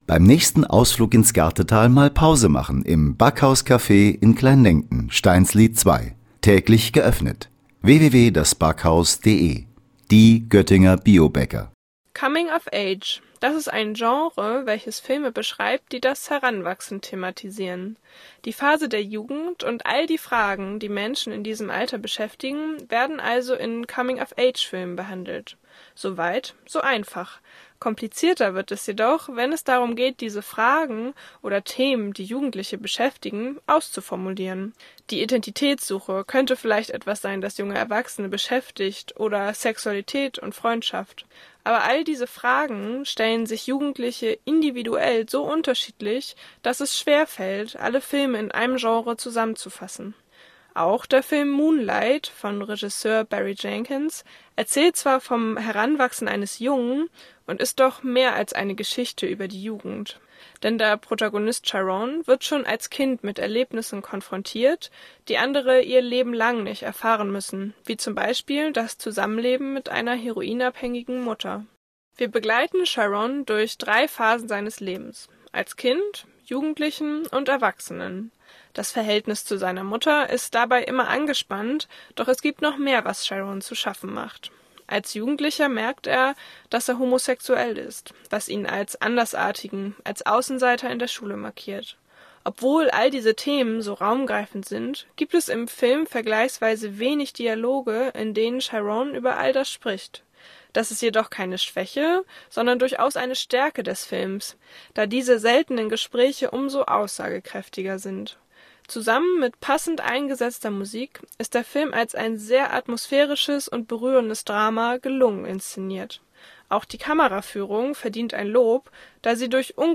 Beiträge > Die Magie des Mondlichts – Filmrezension „Moonlight“ - StadtRadio Göttingen